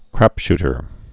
(krăpshtər)